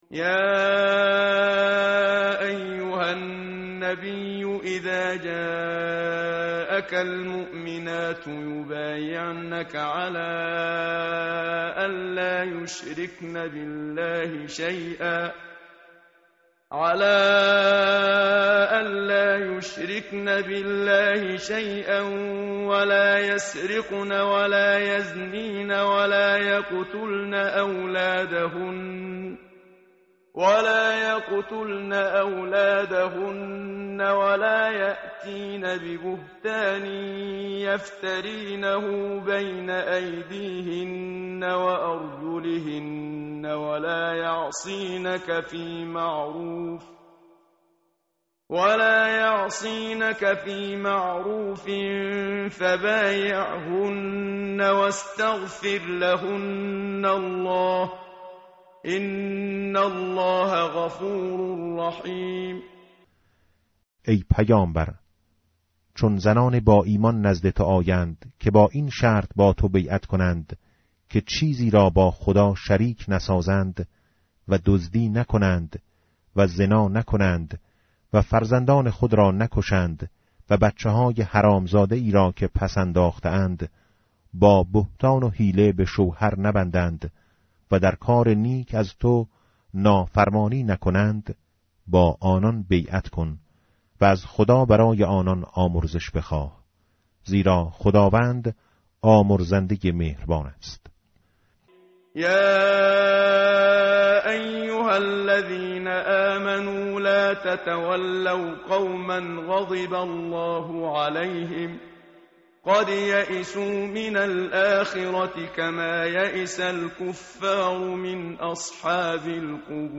tartil_menshavi va tarjome_Page_551.mp3